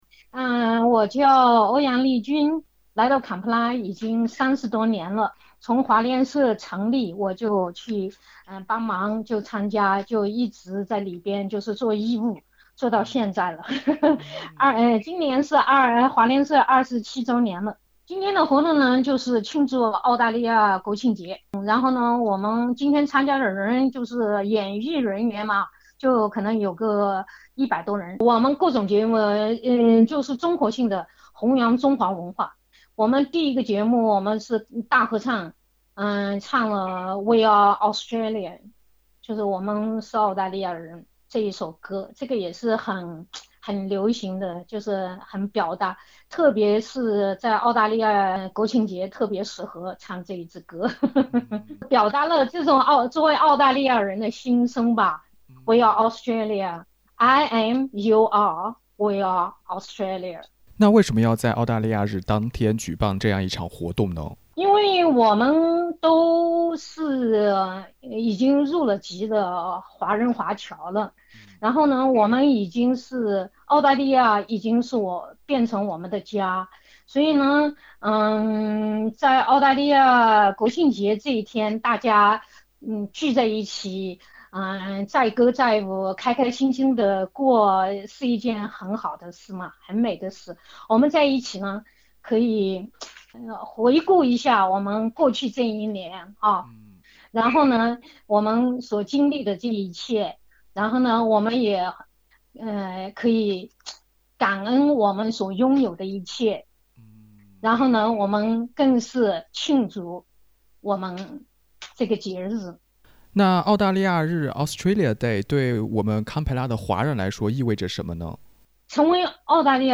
1月26日，堪培拉华人在象征中澳友谊的北京花园举办活动庆祝澳大利亚日（Australia Day），邀请所有堪培拉人前来体验腰鼓、太极、武术等传统中国文化。